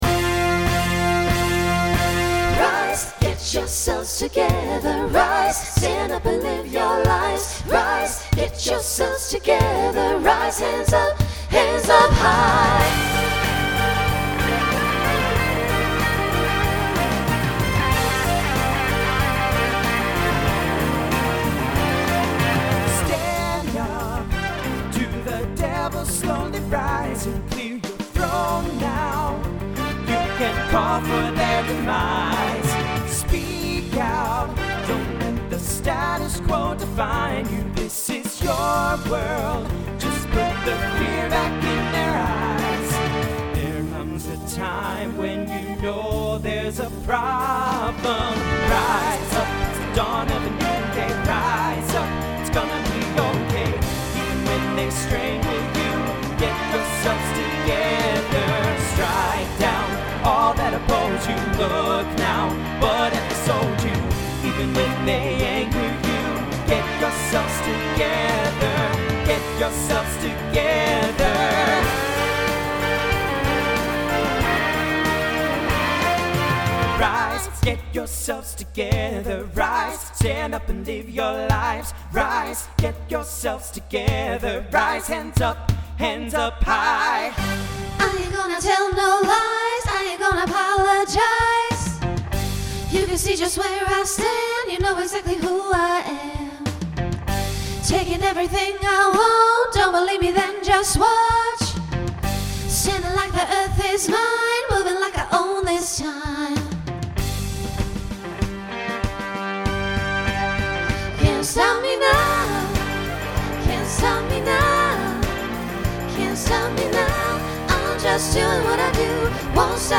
SATB/TTB/SSA/SATB
Genre Rock Instrumental combo
Transition Voicing Mixed